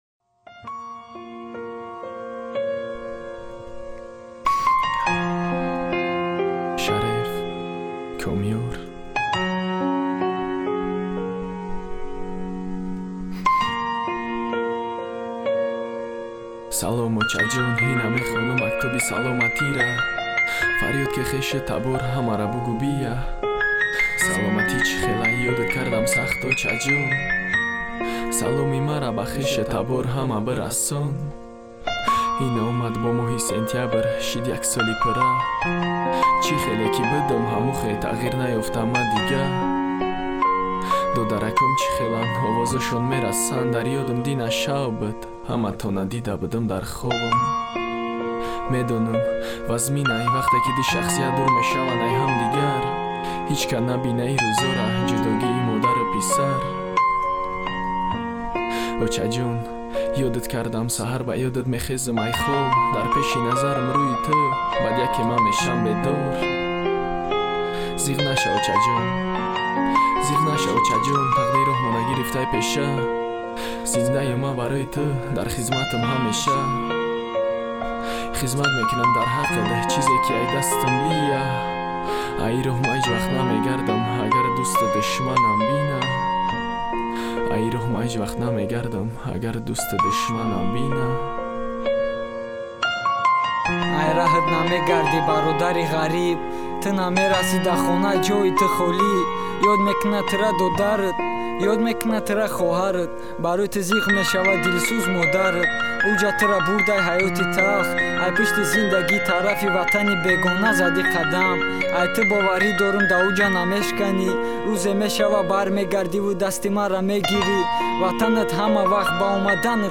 Главная » Файлы » Каталог Таджикских МР3 » Тадж. Rap